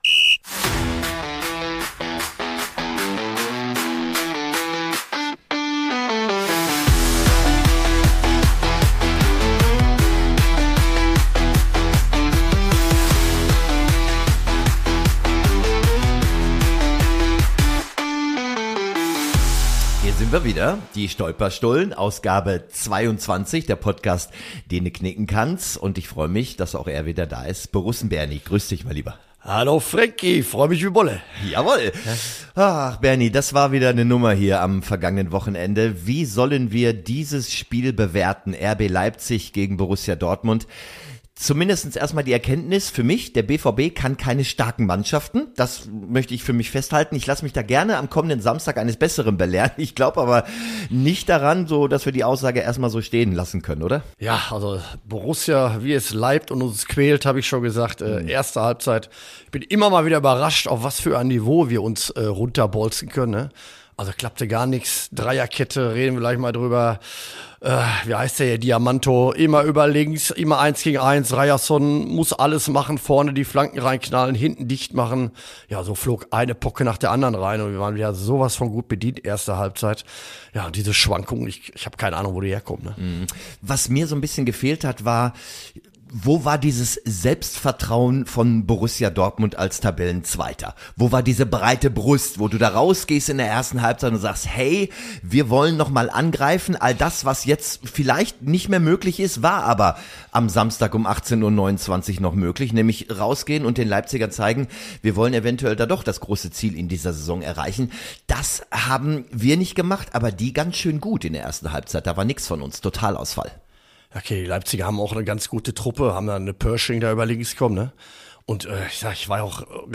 Spoiler: Es war… intensiv. „Die Stolperstullen“ – der Podcast, in dem Fußball analysiert, VAR verurteilt und plötzlich zu Synthie-Beats mitgesungen wird.